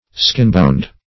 Meaning of skinbound. skinbound synonyms, pronunciation, spelling and more from Free Dictionary.